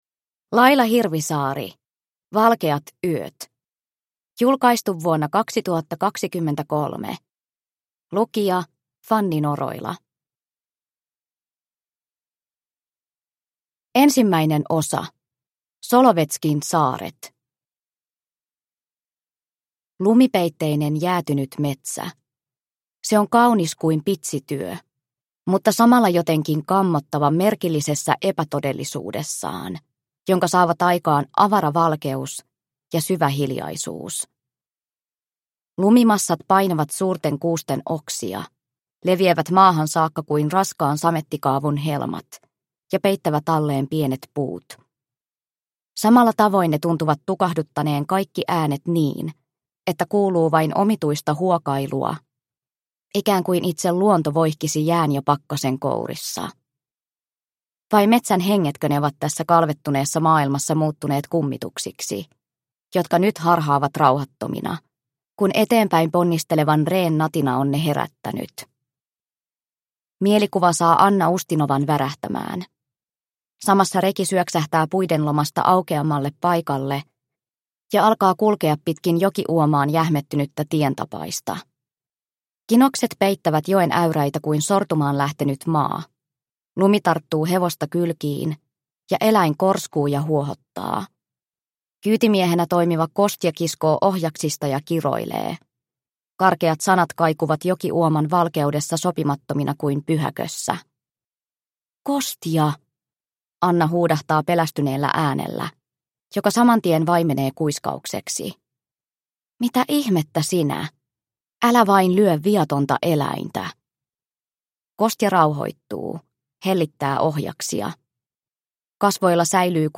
Valkeat yöt – Ljudbok – Laddas ner